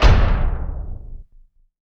LC IMP SLAM 4C.WAV